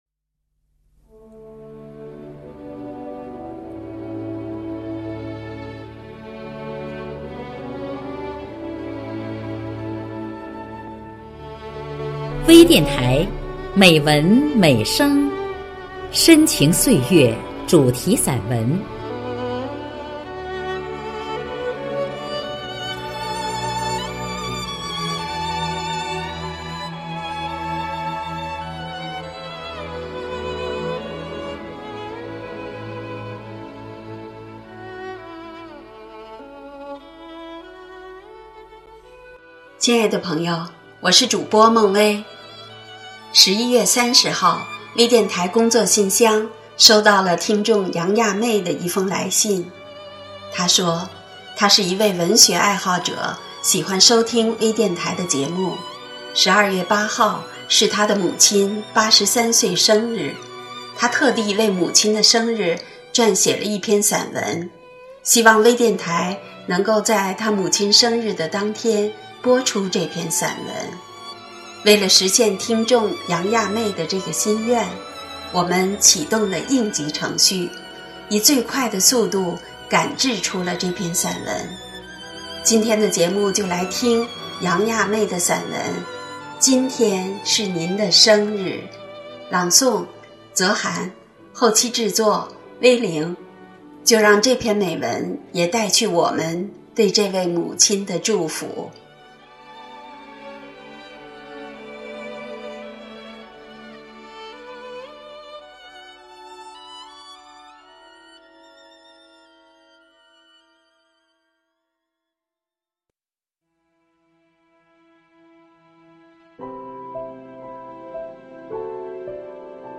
朗诵
多彩美文 专业诵读 精良制作 精彩呈现